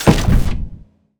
sci-fi_weapon_deep_blaster_shot_02.wav